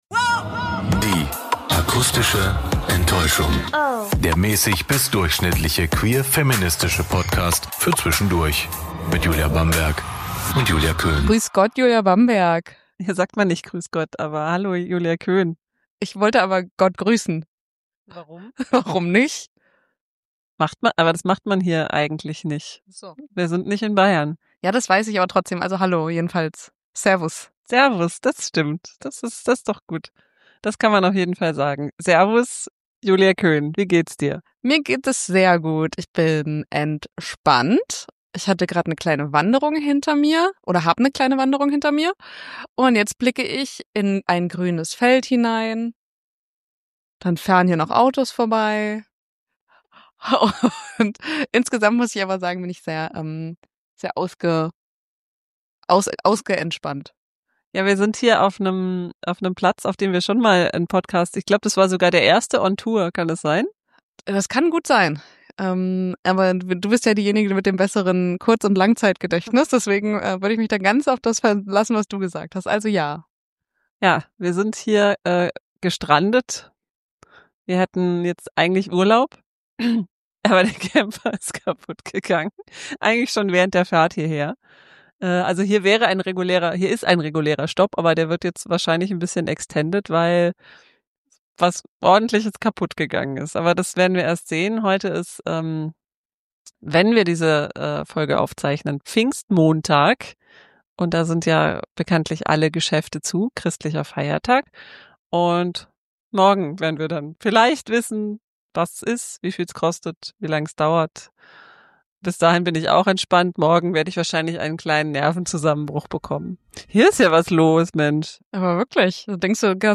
Wir sind mal wieder unterwegs - zusammen in Süddeutschland.
Ps: diese Folge ist wurde ungeschnitten veröffentlicht - seht uns die 'Ähhs' nach :) Mehr